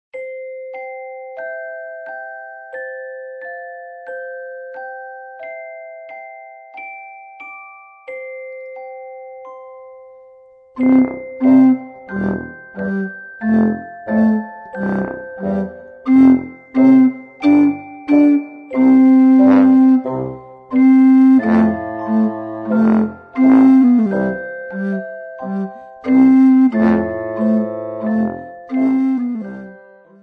Besetzung: Sopranblockflöte